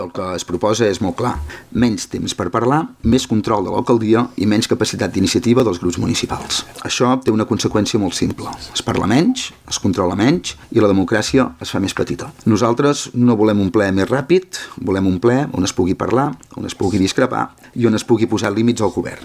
Recuperem les seves intervencions durant la sessió d’abril:
Albert Comas, portaveu CUP: